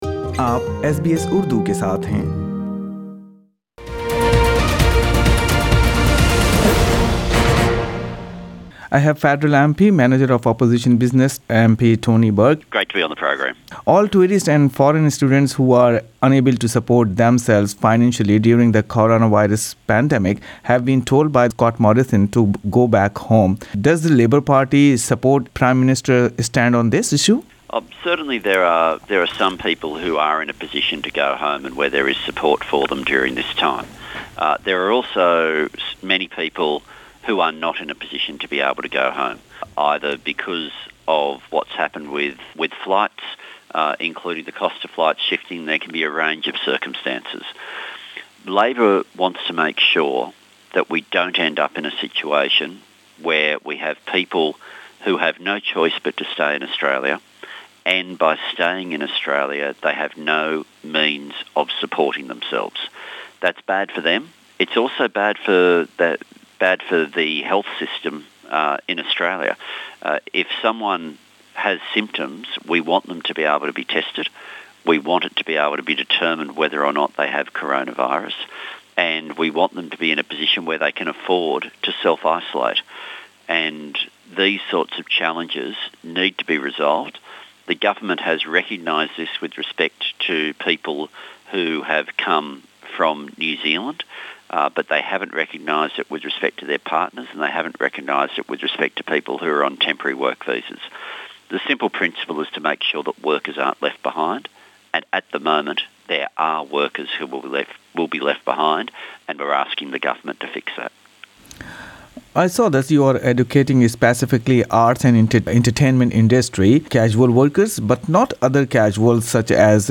Talking to SBS Urdu the Labor MP, Tony Burke says people in many areas are being affected including retail, hospitality, construction, teachers and those in the arts and entertainment sector amid COVID-19 pandemic.